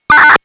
457.935 - FRED - You'll hear a
sound every minute or so when a freight train is nearby.